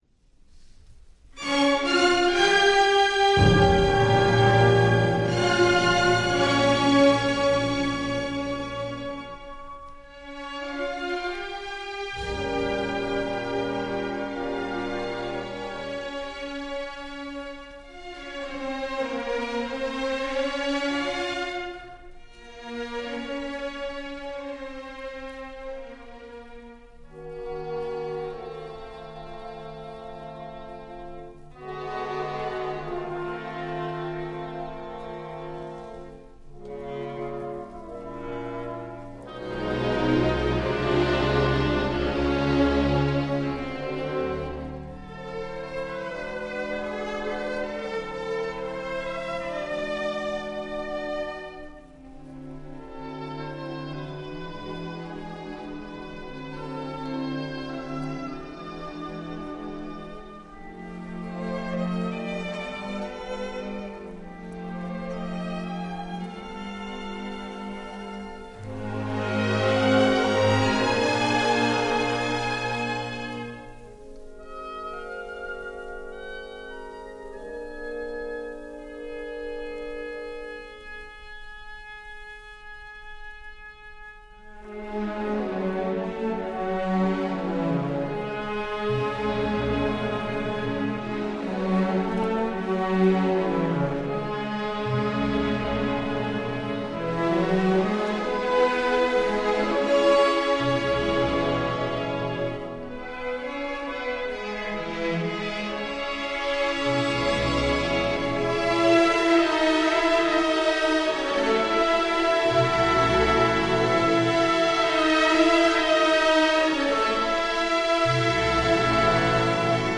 registrazione in studio.